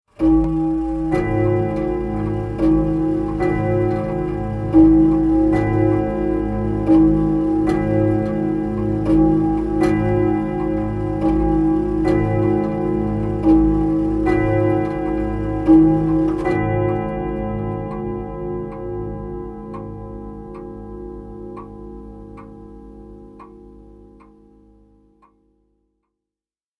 جلوه های صوتی
دانلود صدای ساعت 29 از ساعد نیوز با لینک مستقیم و کیفیت بالا